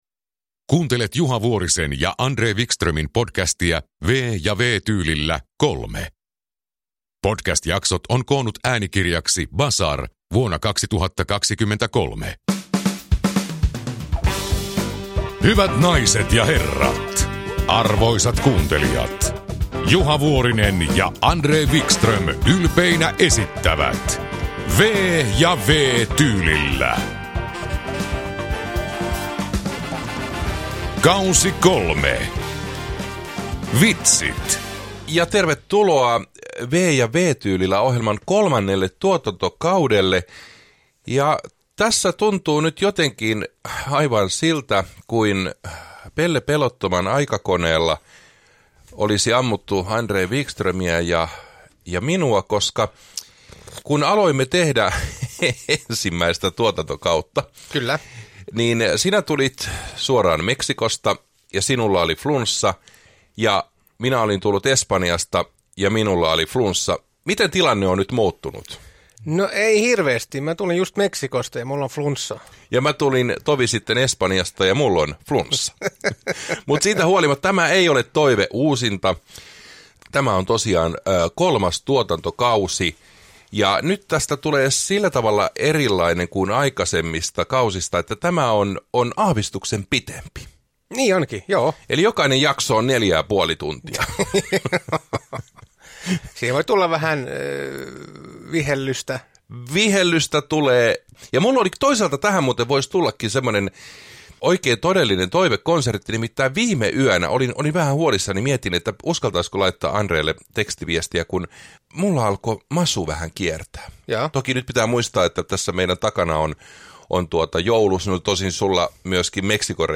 V- ja W-tyylillä K3 – Ljudbok
Uppläsare: Juha Vuorinen, André Wickström